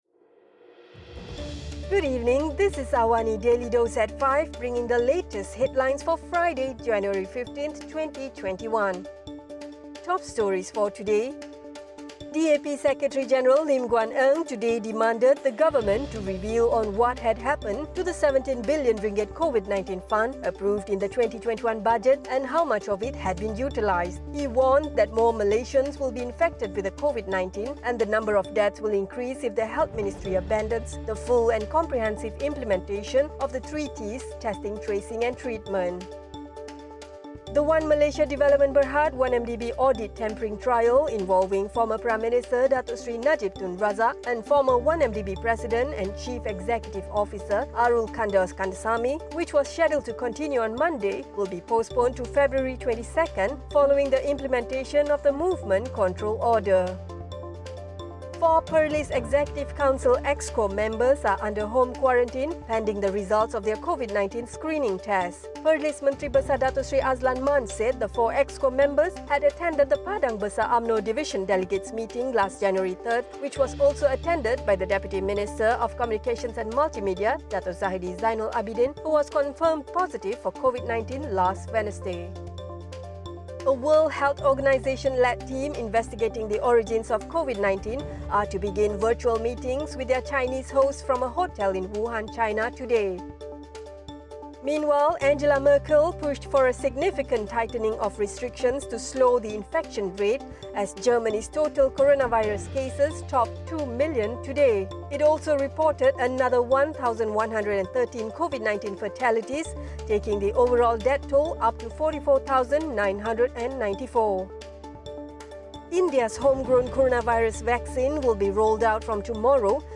Listen to the top stories of the day, reporting from Astro AWANI newsroom — all in 3-minutes.